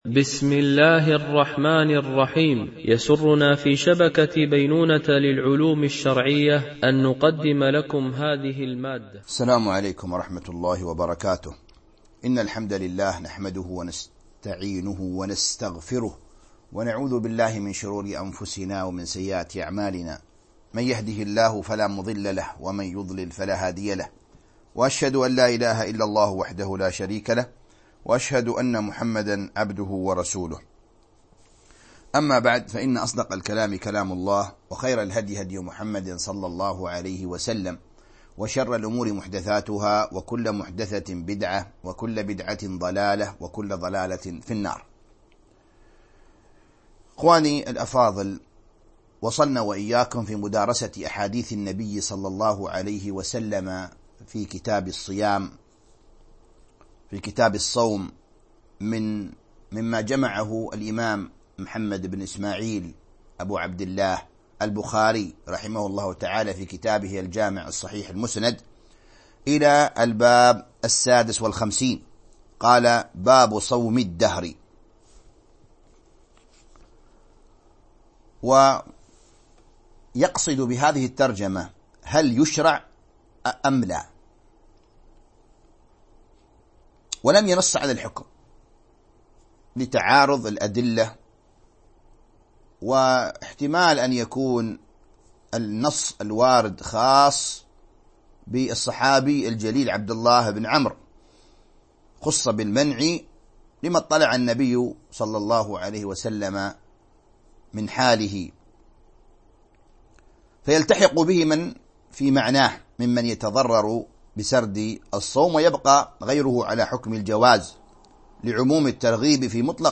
التعليق على كتاب الصيام من صحيح البخاري ـ الدرس 15